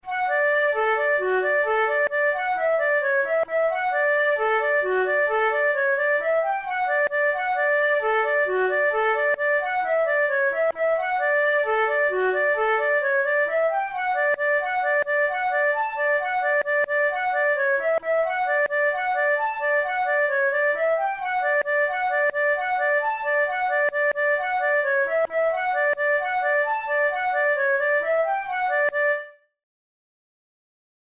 Traditional American tune
Categories: Reels Traditional/Folk Difficulty: easy
james-river-reel.mp3